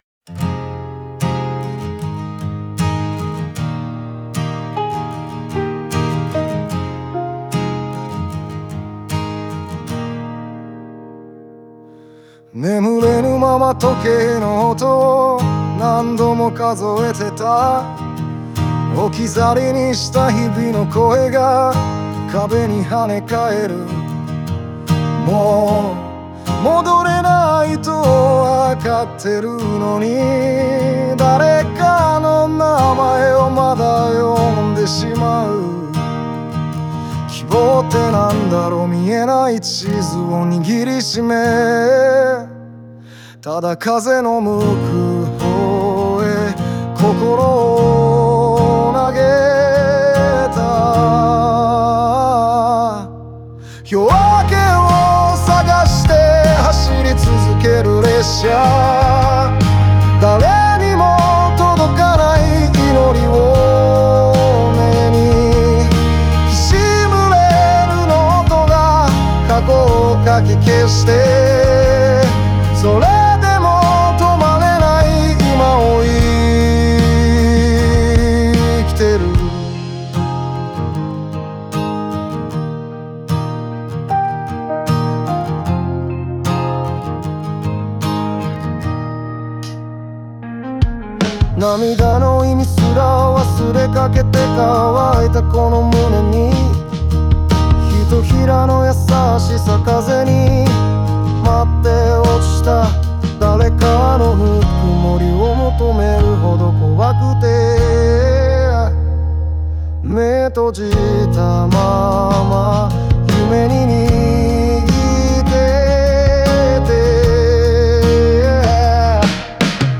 静と動のコントラストを持つ曲構成と、感情の波をなぞるような歌声が、内面の葛藤と再生の過程をリアルに表現しています。